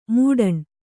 ♪ mūḍaṇ